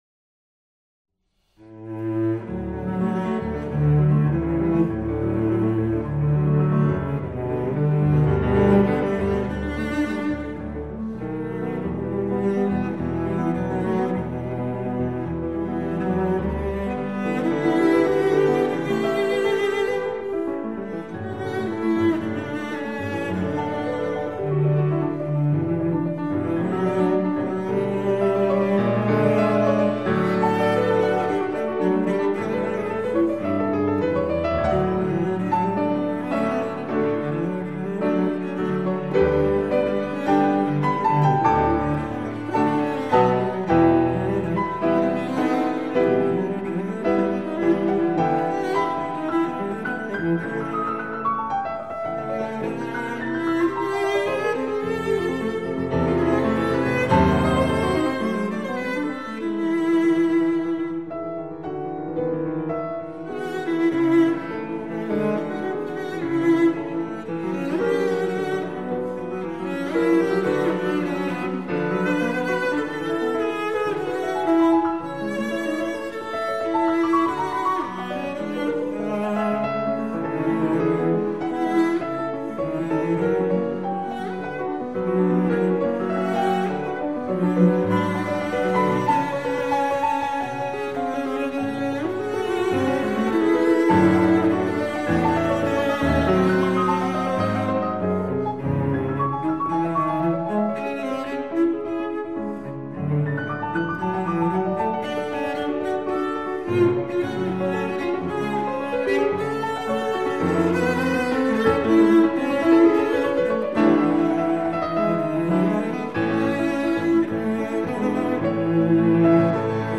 Soundbite 1st Movt
Sonata for Cello and Piano in D Major, Op.17